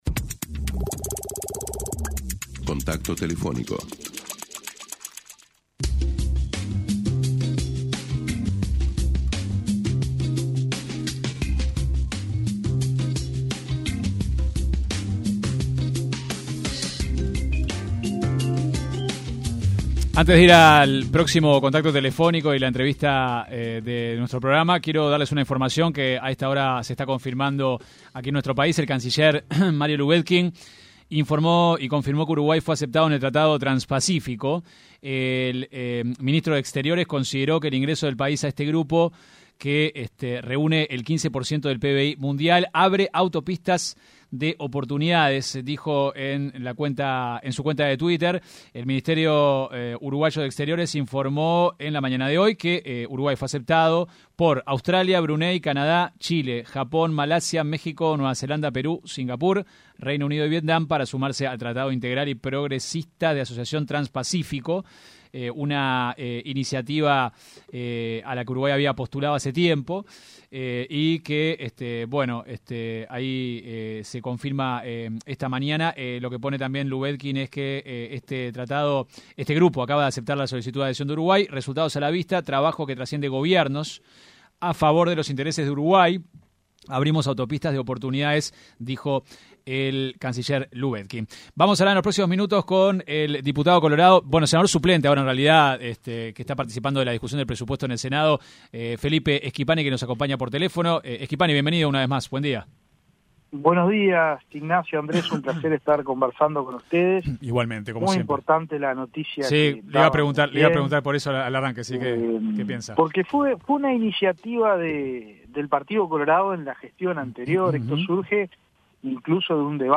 El diputado del Partido Colorado, hoy senador suplente, Felipe Schipani, en entrevista con 970 Noticias, destacó la iniciativa que Uruguay inició durante la gestión de Luis Lacalle Pou y el exvicecanciller, Nicolás Albertoni, lideró las negociaciones hasta que asumió la nueva administración.